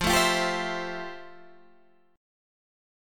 FM9 Chord
Listen to FM9 strummed